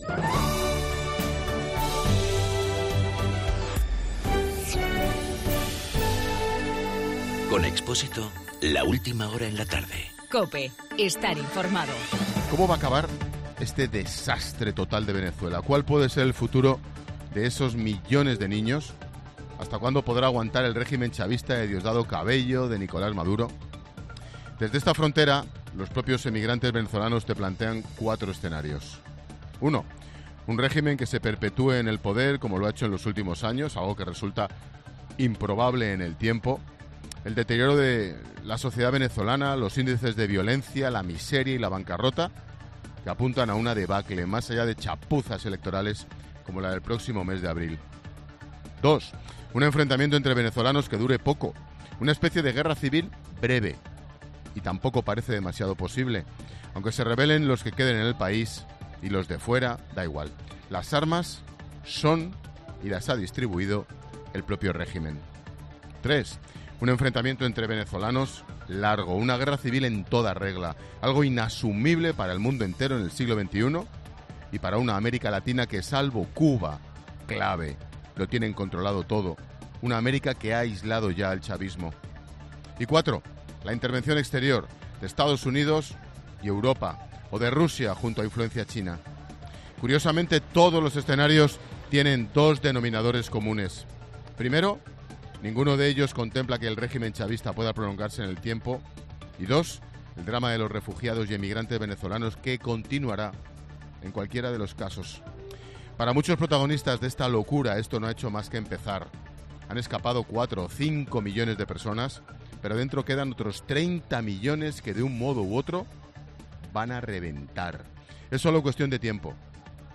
AUDIO: Ángel Expósito analiza la crisis de Venezuela desde el puente Simón Bolivar, en la frontera Colombia- Venezuela.